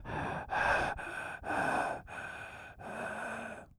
Male_Breathing_01.wav